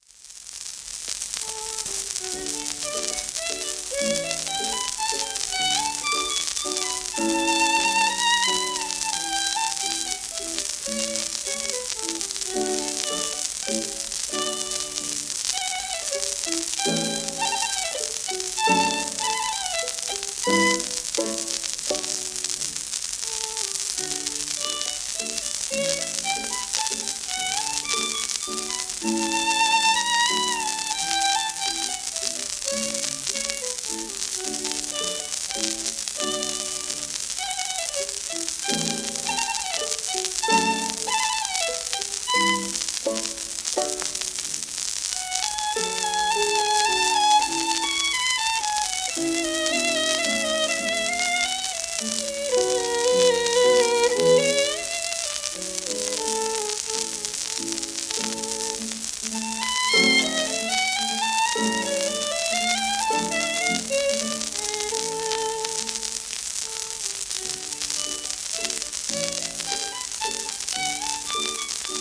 アーサー・カッテラル(Vn:1883-1943)
w/ピアノ
シェルマン アートワークスのSPレコード